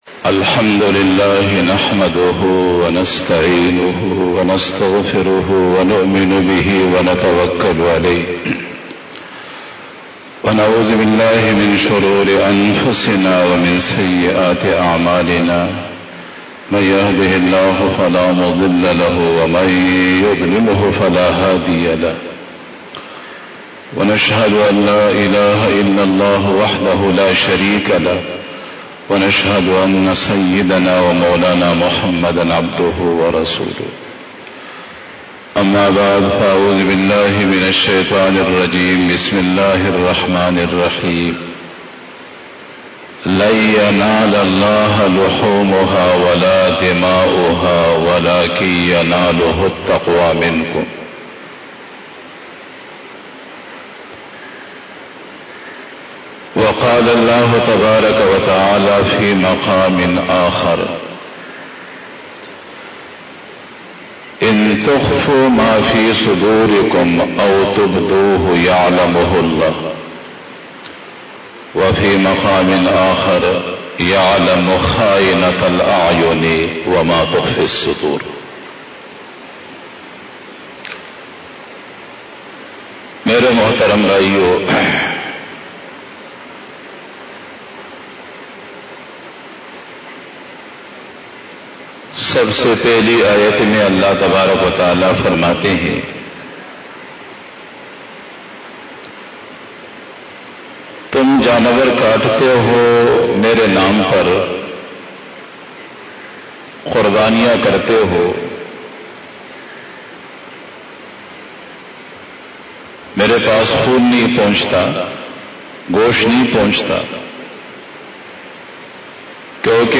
10 BAYAN E JUMA TUL MUBARAK 05 March 2021 20 Rajab 1442H)
02:18 PM 592 Khitab-e-Jummah 2021 --